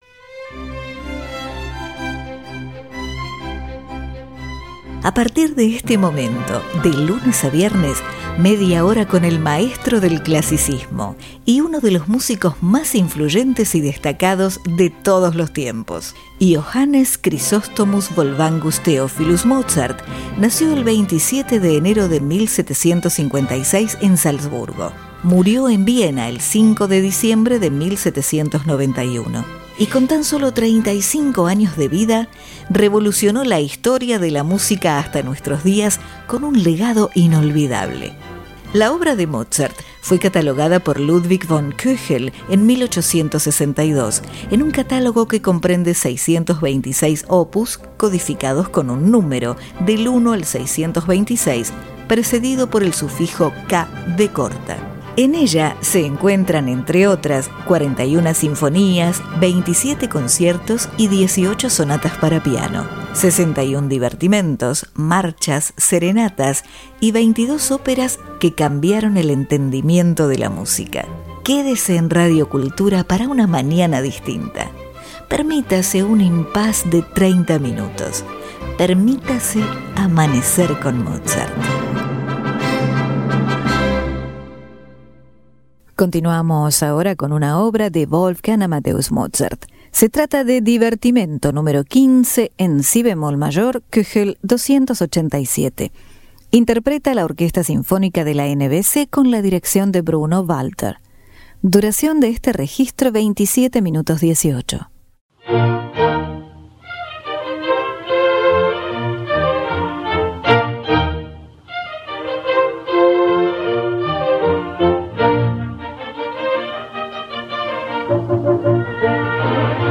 Divertimento Nº 15 En Si Bemol Mayor K. 287 Orquesta Sinfonica De La NBC Bruno Walter